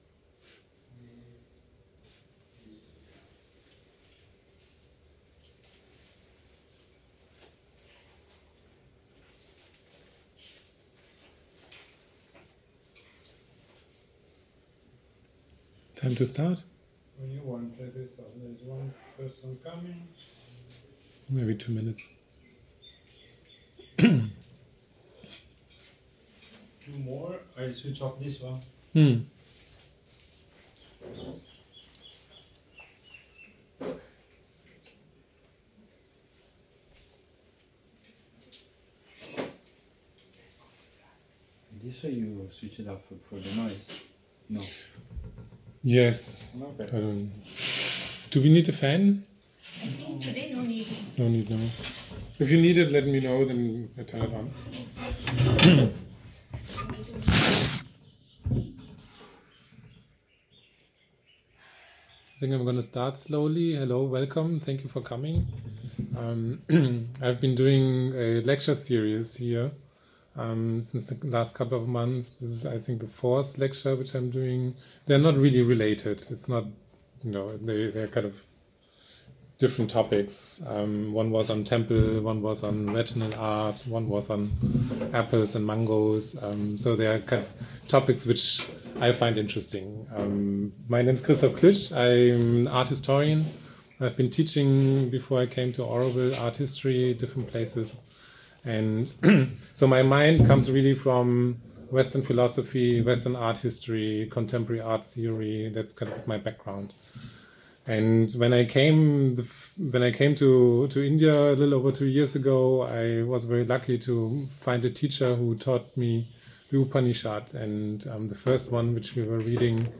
Fourth lecture – Tuesday 7th January 2025 at 5pm